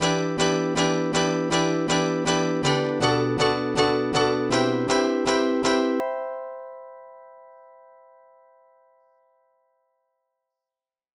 BackYard Melody 3 (HalfSpeed).wav